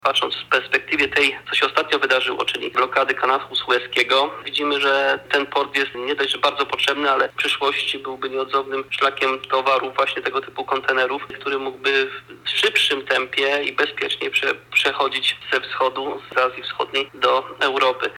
Burmistrz Leszek Kopeć podkreśla, że pieniądze zostaną wykorzystane na dokumentację projektową, która jest już tworzona.